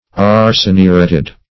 Search Result for " arseniuretted" : The Collaborative International Dictionary of English v.0.48: Arseniureted \Ar`se*ni"u*ret`ed\, a. (Chem.)
arseniuretted.mp3